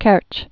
(kĕrch)